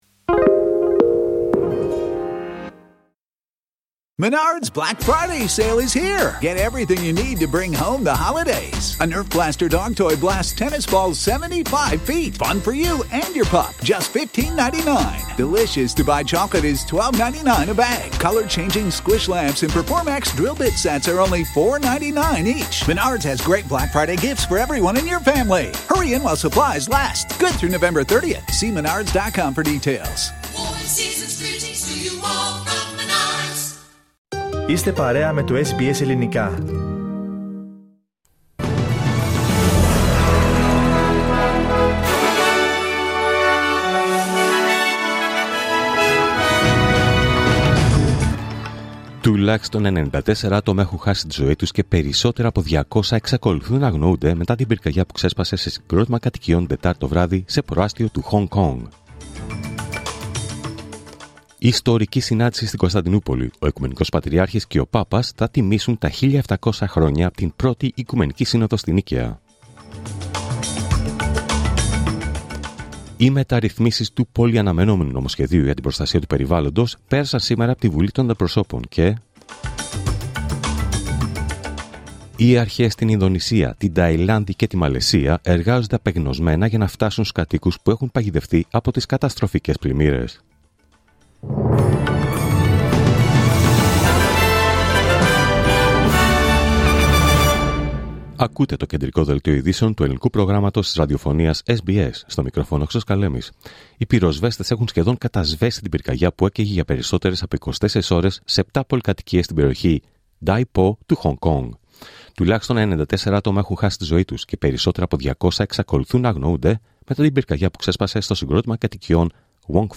Δελτίο Ειδήσεων Παρασκευή 28 Νοεμβρίου 2025